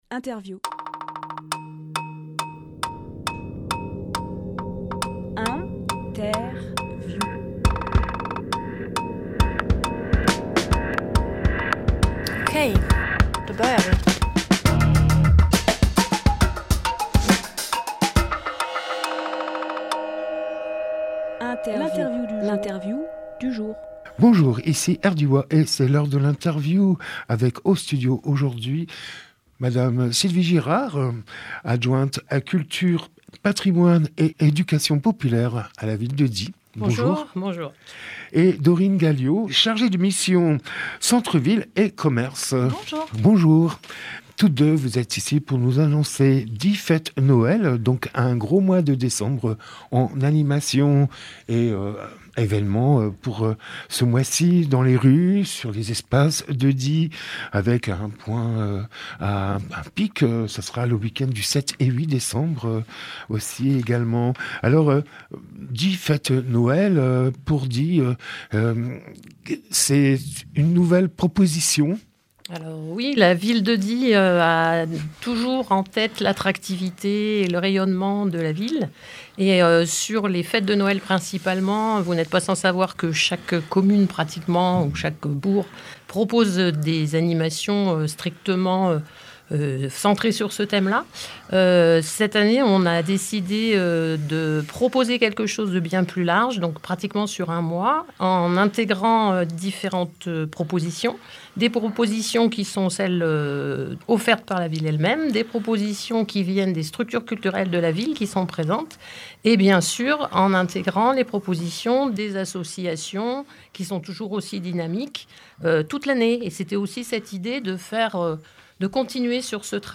Emission - Interview Die Fête Noël tout le mois de décembre Publié le 21 novembre 2024 Partager sur…
lieu : studio Rdwa